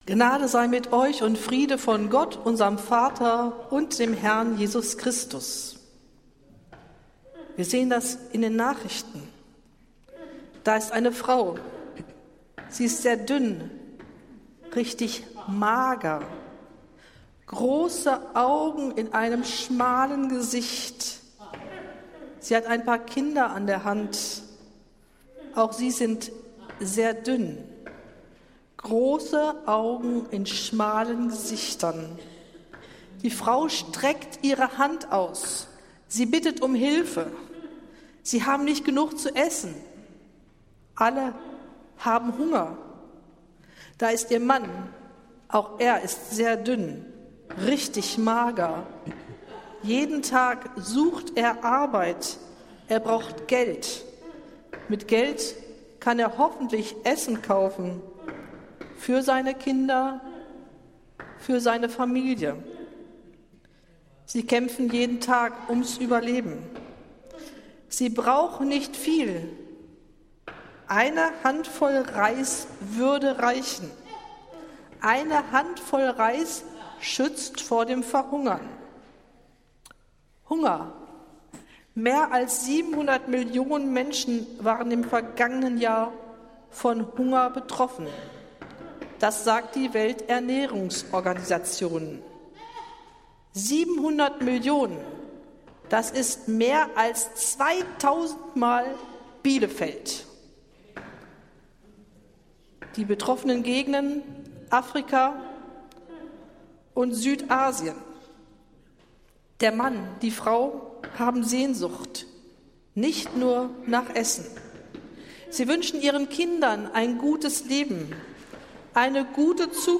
Predigt des Gottesdienstes in einfacher Sprache aus der Zionskirche am Sonntag, den 3. August 2025
Wir haben uns daher in Absprache mit der Zionskirche entschlossen, die Predigten zum Nachhören anzubieten.